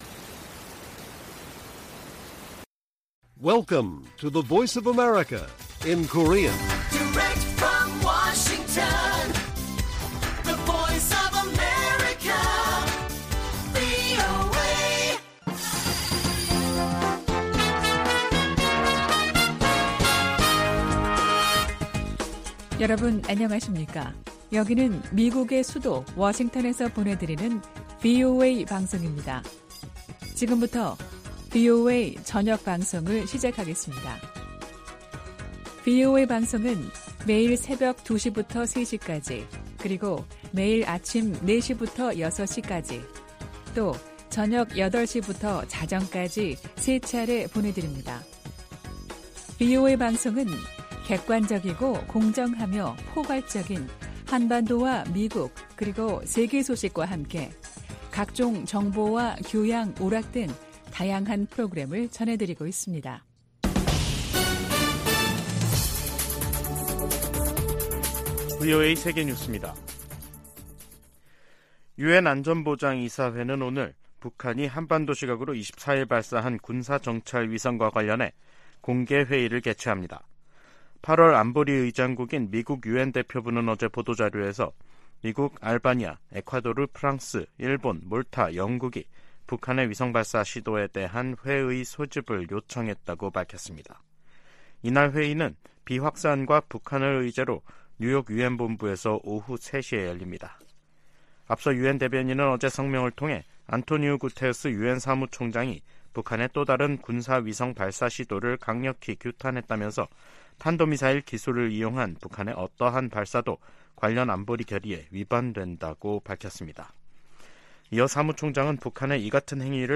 VOA 한국어 간판 뉴스 프로그램 '뉴스 투데이', 2023년 8월 25일 1부 방송입니다. 유엔 안전보장이사회가 25일 미국 등의 요청으로 북한의 위성 발사에 대한 대응 방안을 논의하는 공개 회의를 개최합니다. 미 국방부가 북한의 2차 정찰위성 발사를 비판하며 지역의 불안정을 초래한다고 지적했습니다. 북한 해킹조직이 탈취한 거액의 암호화폐를 자금세탁해 현금화할 가능성이 있다고 미국 연방수사국(FBI)이 경고했습니다.